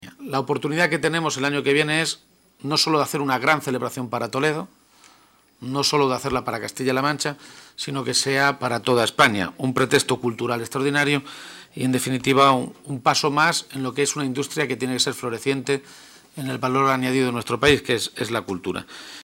García-Page en la comisión de Cultura del Senado
Cortes de audio de la rueda de prensa